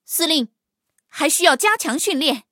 黑豹查看战绩语音.OGG